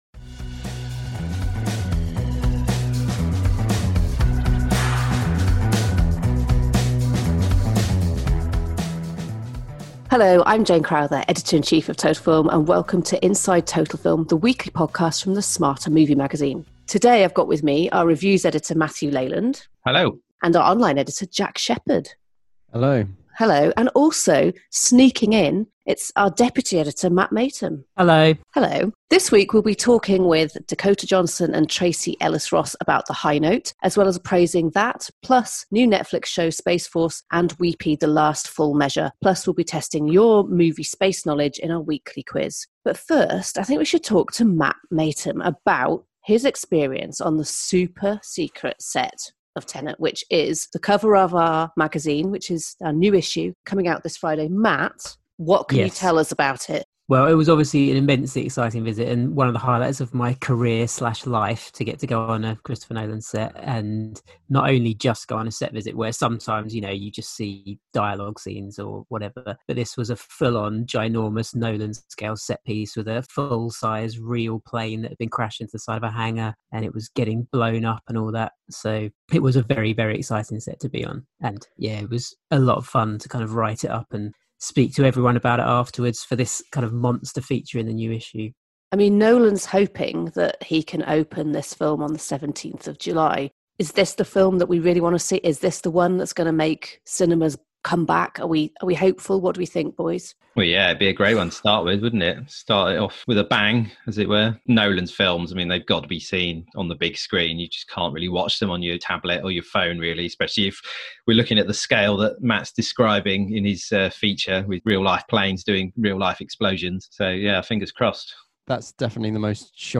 Interviews with Dakota Johnson and Tracee Ellis Ross